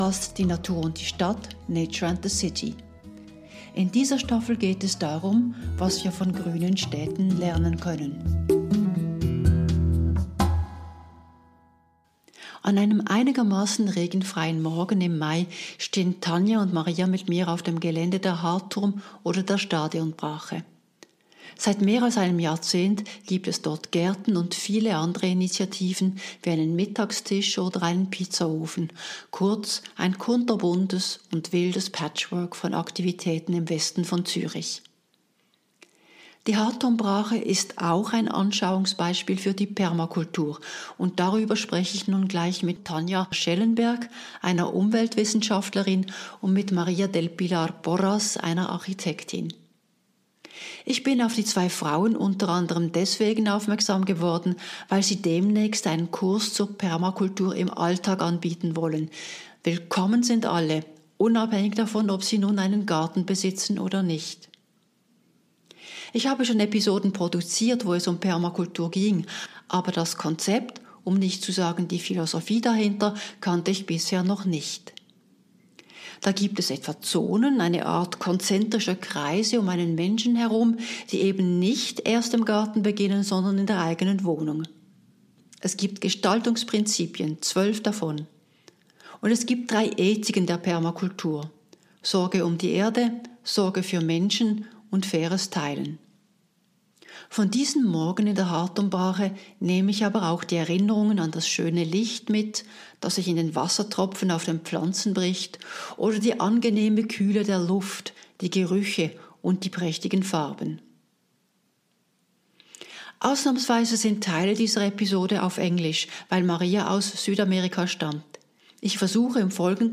An einem regenfreien Morgen im Mai stehe ich mit meinen zwei Interviewpartnerinnen auf dem Gelände der Hardturm-Brache.